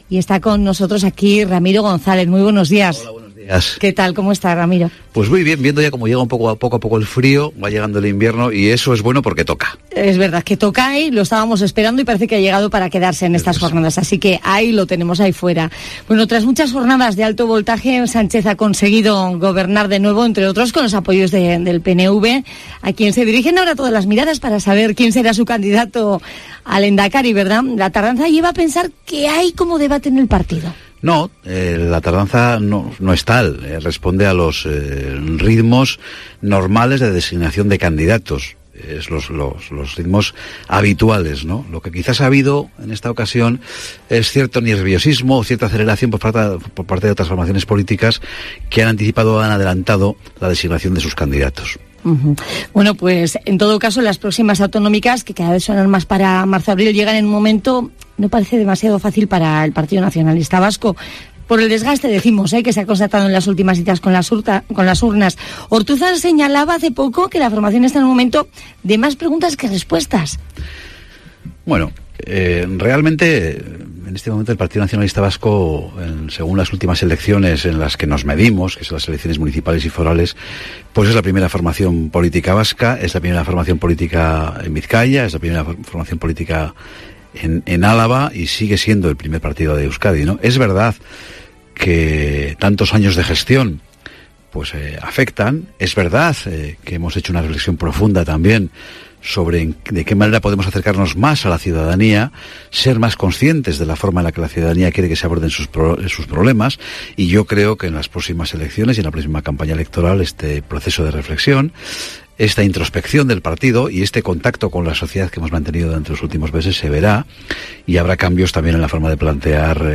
Ramiro González, diputado general dem Álava en Cope Euskadi